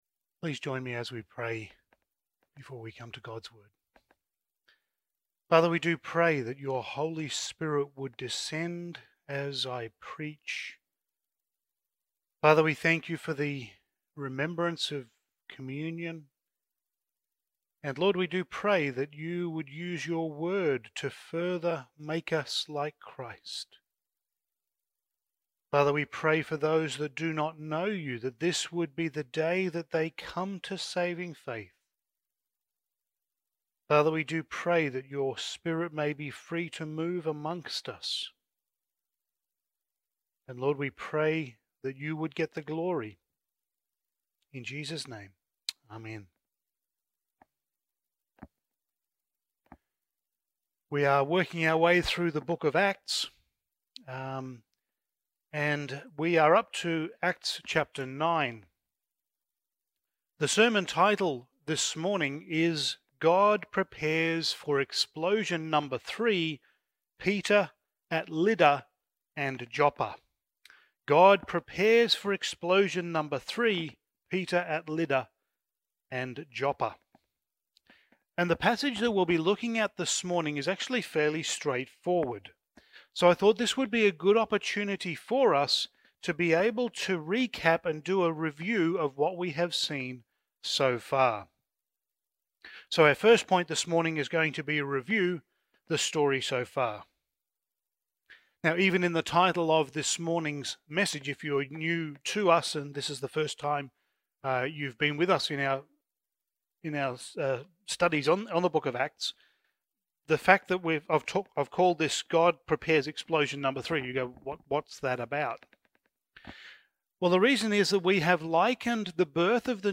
Passage: Acts 9:32-43 Service Type: Sunday Morning